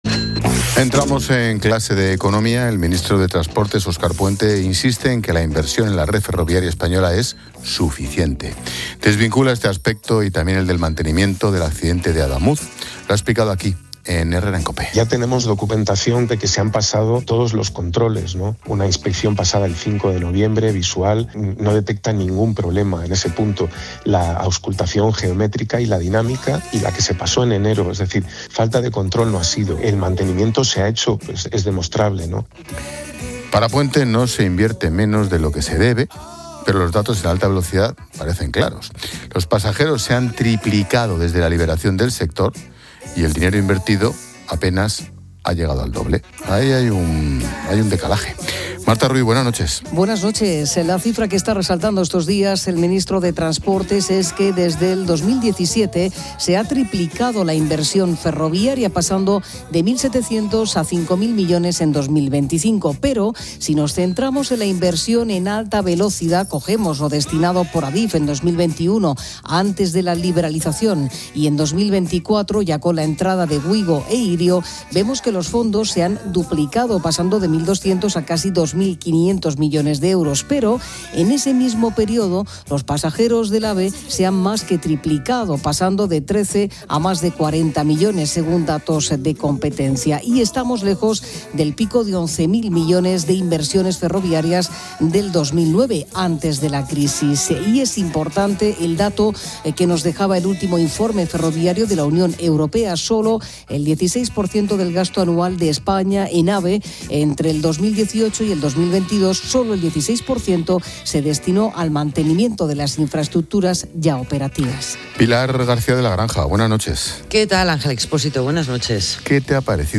Ángel Expósito aprende en Clases de Economía de La Linterna con la experta económica y directora de Mediodía COPE, Pilar García de la Granja, sobre el accidente ferroviario en Adamuz y el debate sobre la inversión